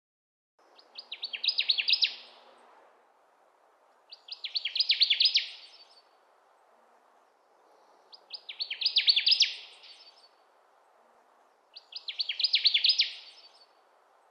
Djur , Fågelsång